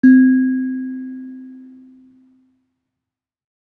kalimba1_circleskin-C3-mf.wav